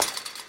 Shotgun_UnloadMagazine_2.wav